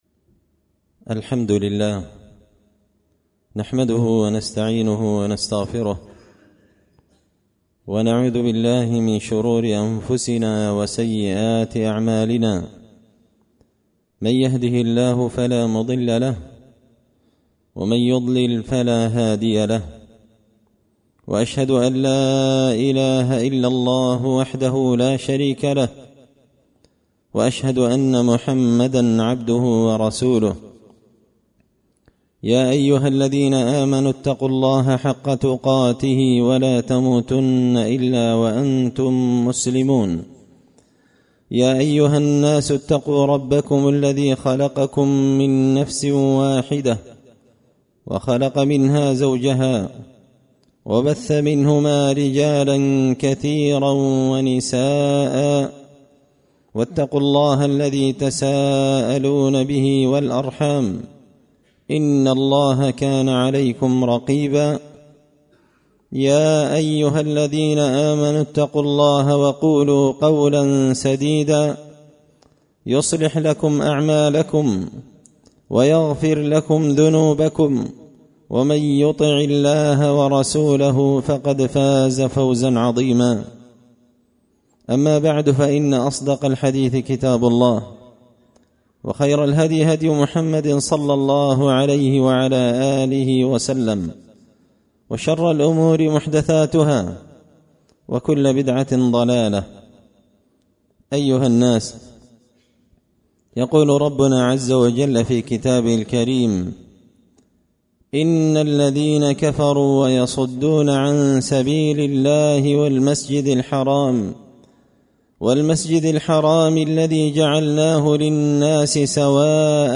خطبة جمعة بعنوان – من أحكام الأضحية
دار الحديث بمسجد الفرقان ـ قشن ـ المهرة ـ اليمن